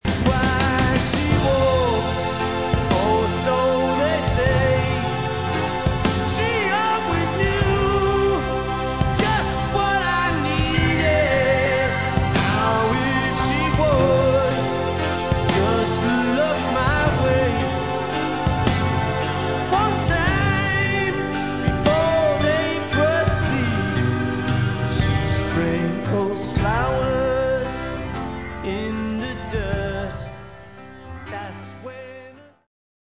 Lead Vocal, Bass
Guitars
Drums, Tambourine
Keyboards
Piano
Horns